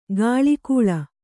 ♪ gāḷikūḷa